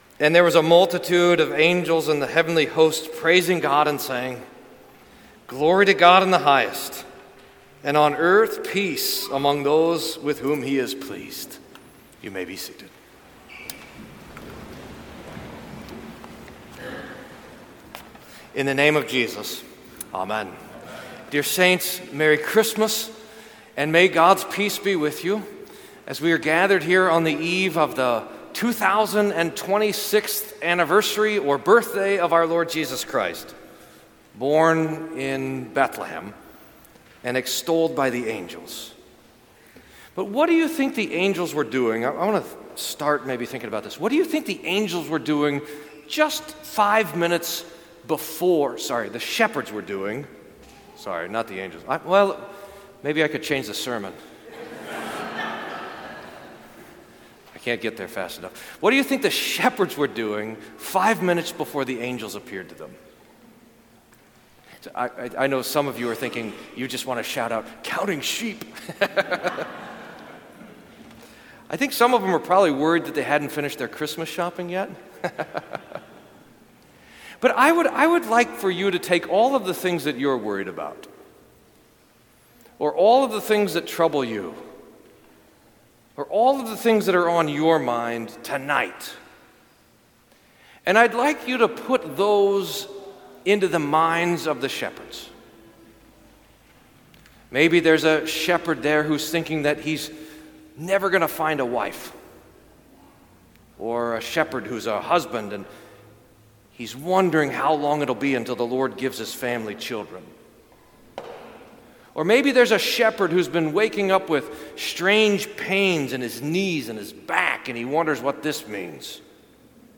Sermon for Christmas Eve